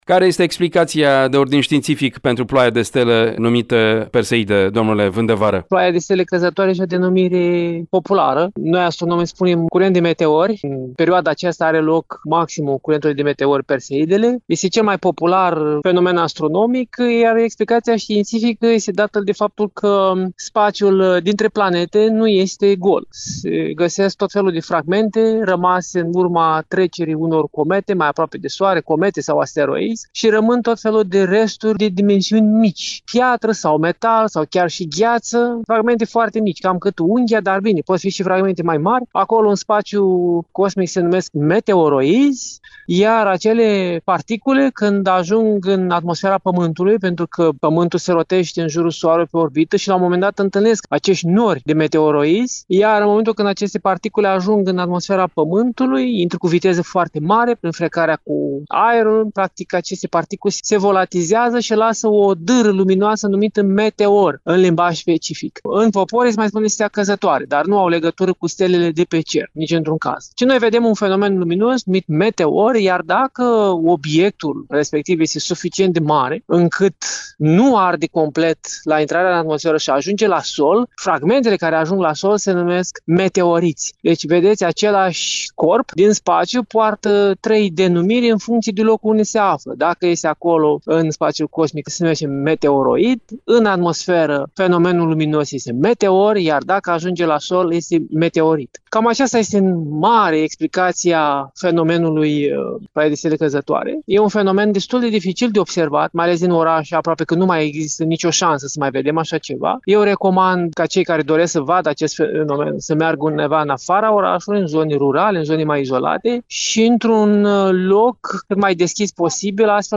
Varianta audio a interviului: